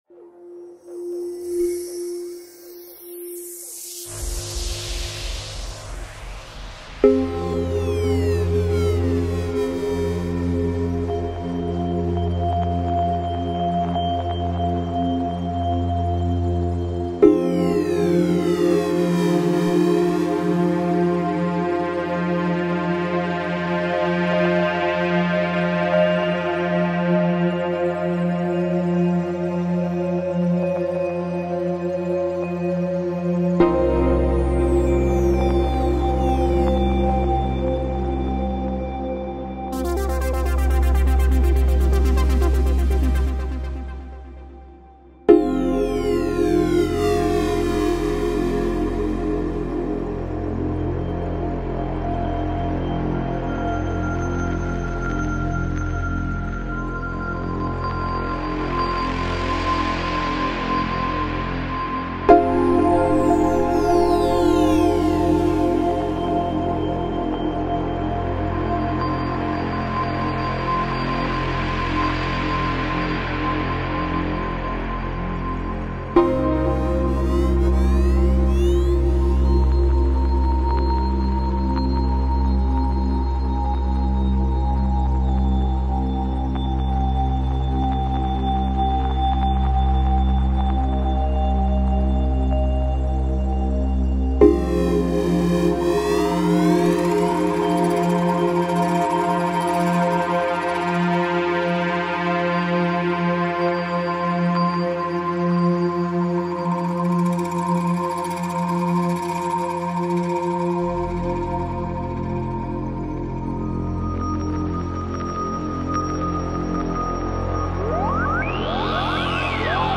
Spire 1.5.11是一款软件复音合成器，结合了强大的声音引擎调制和灵活的体系结构，图形界面提供了无与伦比的可用性。
关于大多数预设，可以说的一件事是它们清晰，定义清晰，具有震撼力，并且具有某种温暖的响度。